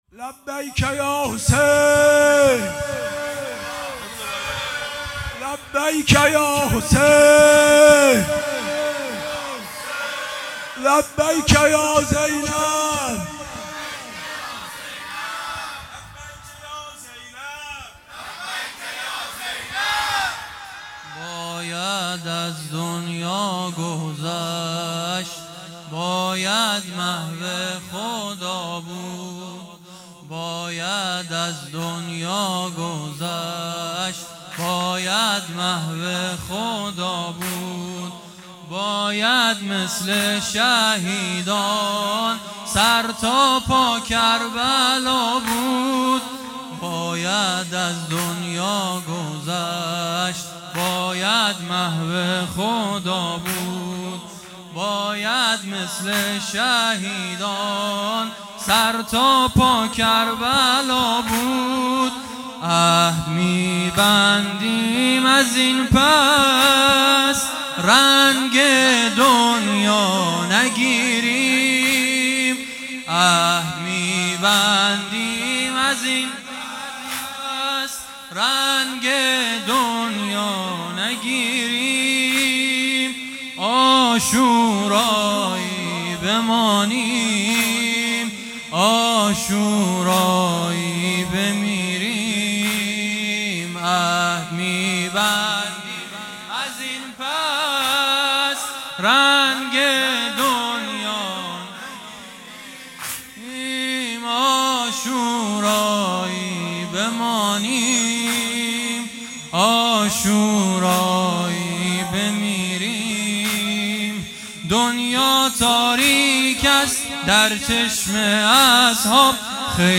مراسم شب دوم عزاداری دهه اول محرم 1445